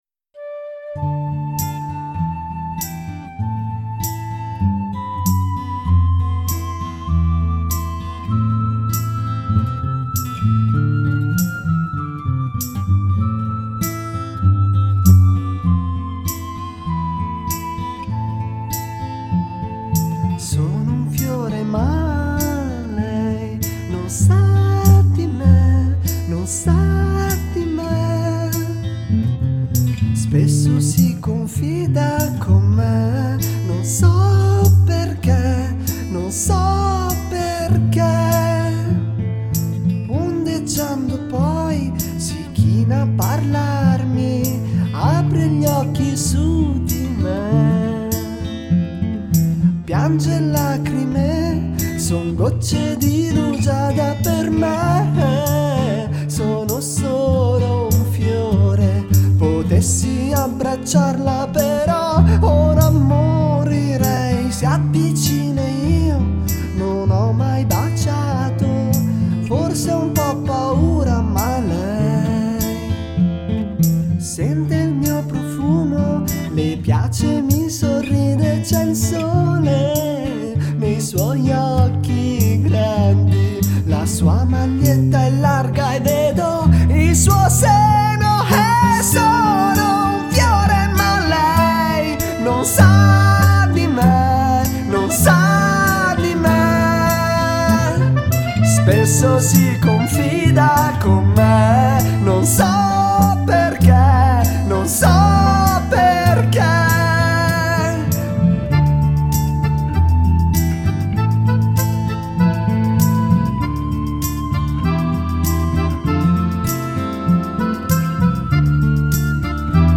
Luogo esecuzioneRealSound Record
folk